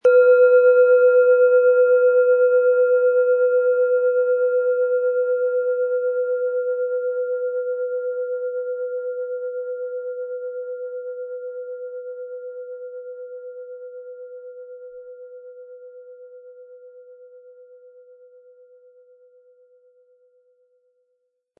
Klangschale tibetisch ca. 13-15 cm handgefertigt Bihar Matt 400-500 gr.
Auch wenn sie nicht so gross ist, hat sie ein gutes Schwingungsverhalten und einen schönen Klang.
Sie hören einen ähnlichen Klang der Planetenschale PL-MAR-500